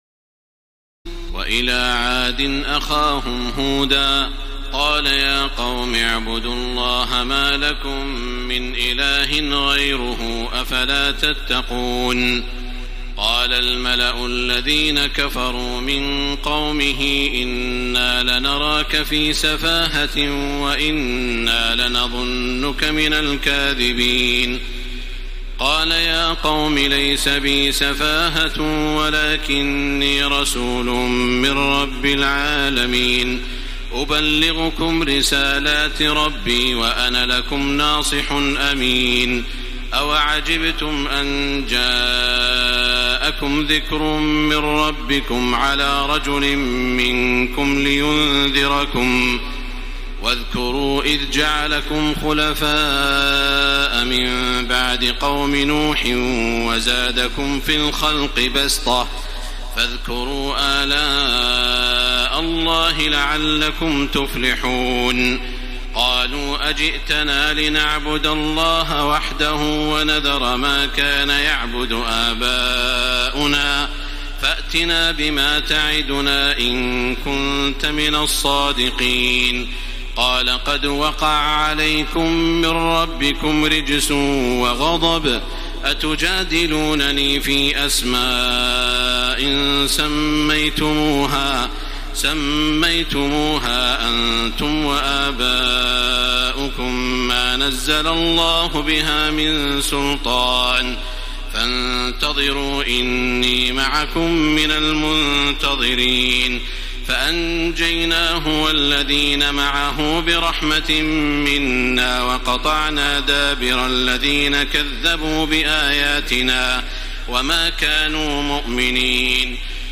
تراويح الليلة الثامنة رمضان 1434هـ من سورة الأعراف (65-162) Taraweeh 8 st night Ramadan 1434H from Surah Al-A’raf > تراويح الحرم المكي عام 1434 🕋 > التراويح - تلاوات الحرمين